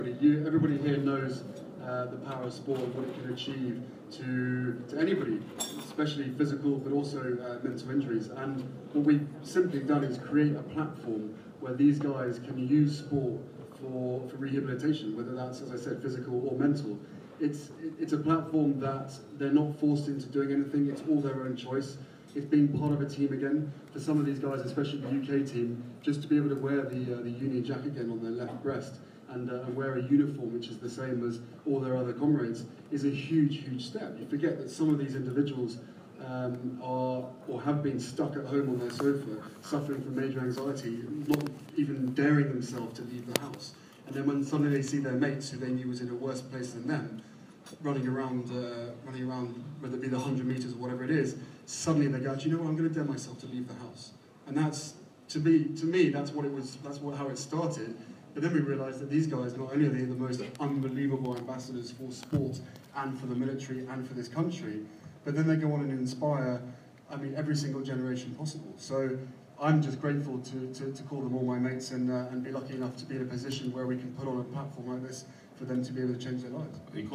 Prince Harry on Invictus Games. Impressive speech at Sport Industry Awards